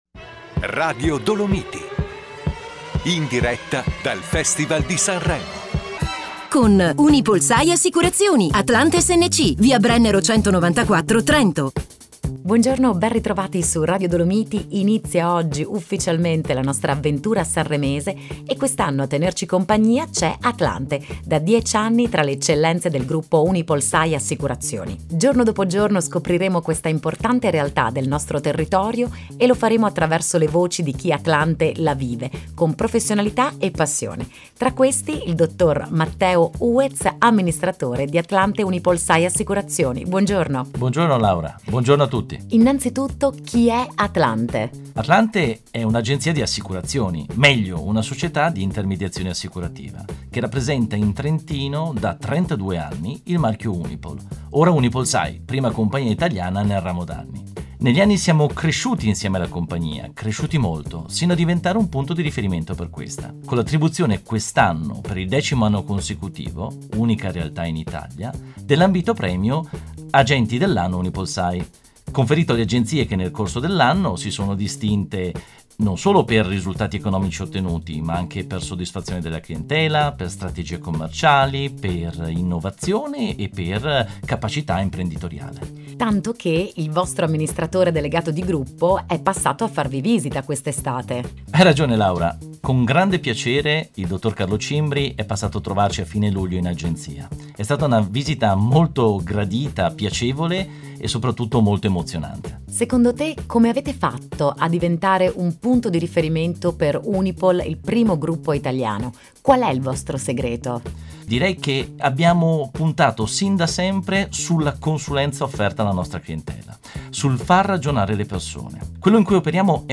Ad inizio febbraio i nostri consulenti sono stati ospiti di Radio Dolomiti. E’ stata l’occasione per presentare la nostra Agenzia ed approfondire temi “caldi” quali la salute, la previdenza integrativa ed il noleggio a lungo termine.
negli studi di Radio Dolomiti